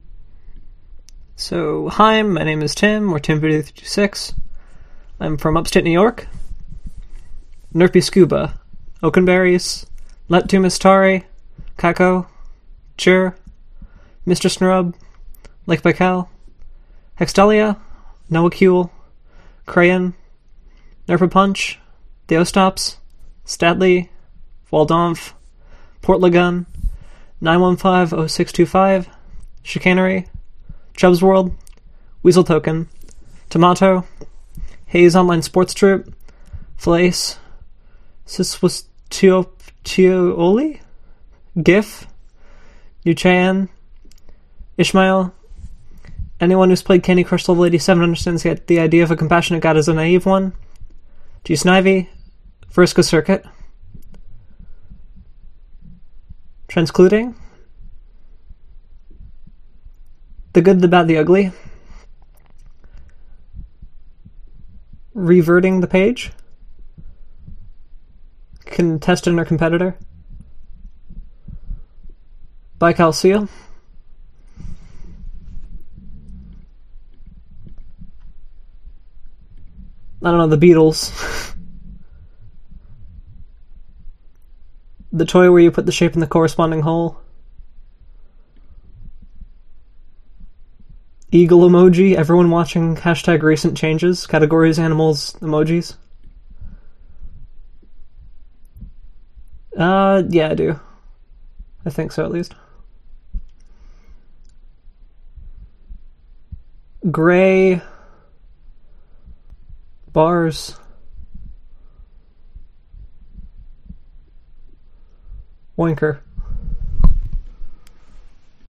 Recordings of ourselves answering those questions.